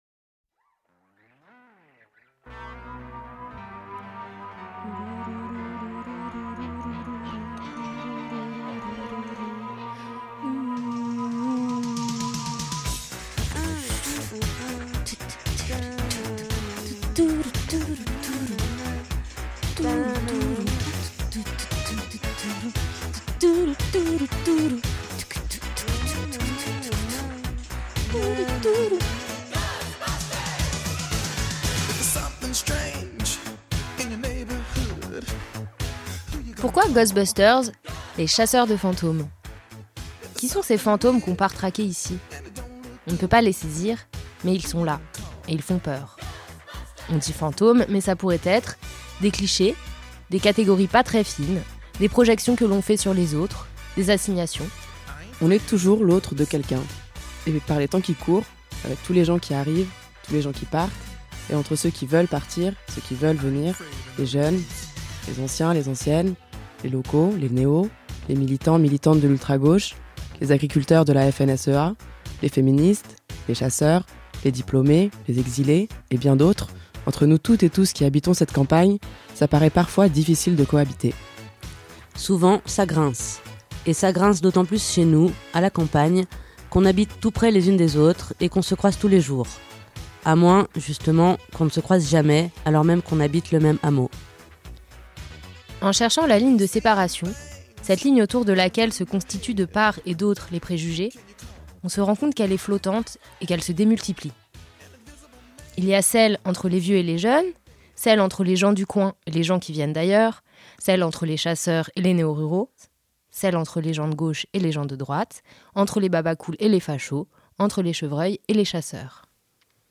Invité(s) : les habitué.e.s du bar de Varen